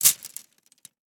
household
Plastic Tent Poles Dropping on Dirt 2